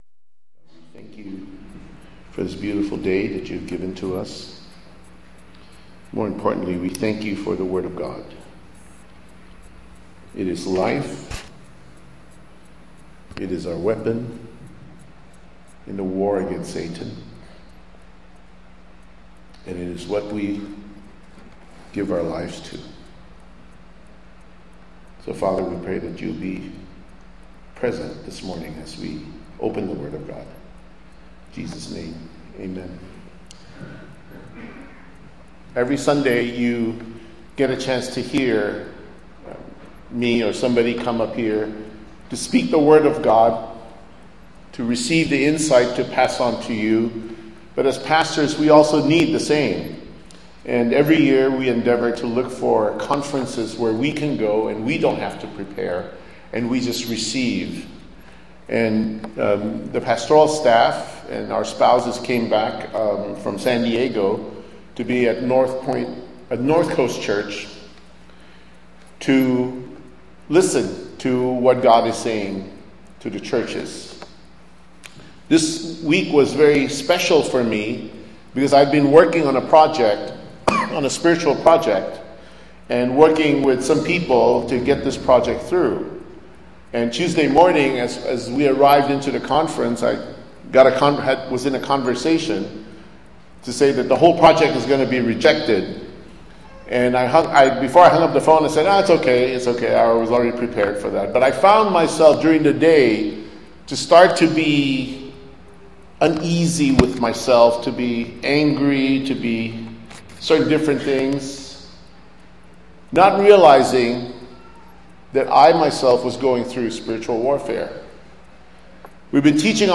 Sermon Topics: spiritual battle